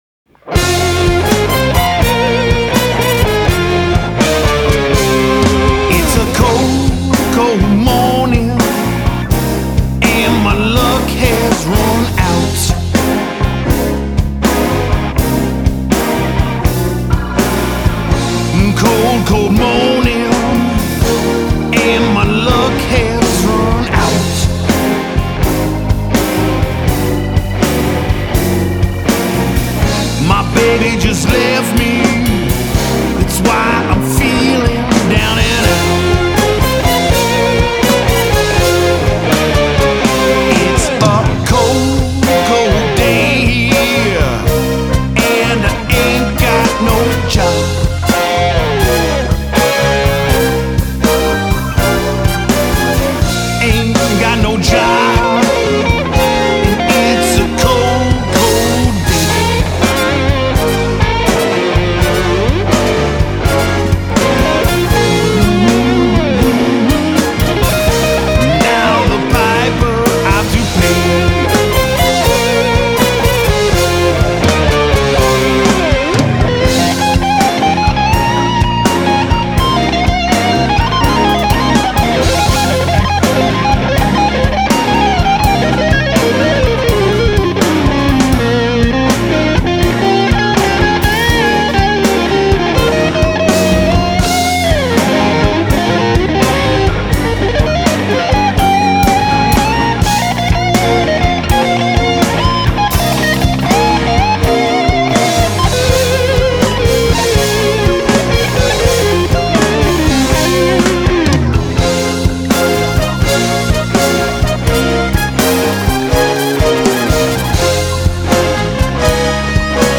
Тип альбома: Студийный
Жанр: Blues-Rock